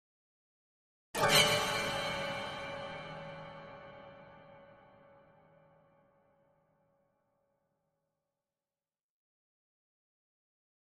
High Strings
Harmonic Plucks Sharp Horror Chord 2